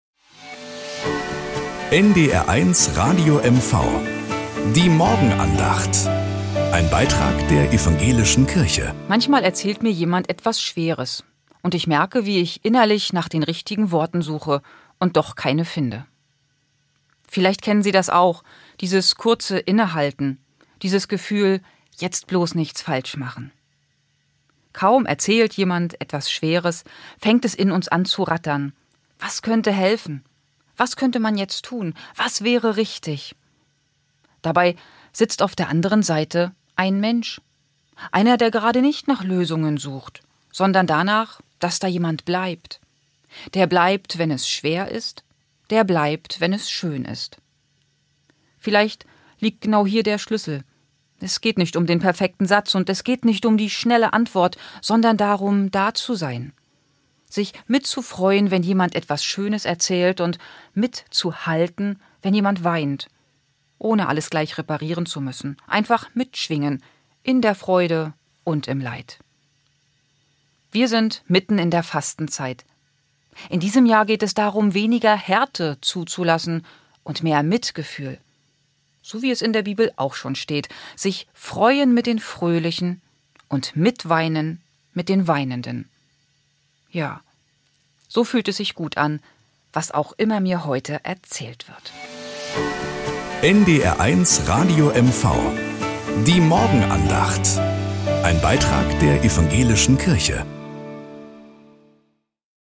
Morgenandacht auf NDR 1 Radio MV
Um 6:20 Uhr gibt es in der Sendung "Der Frühstücksclub" eine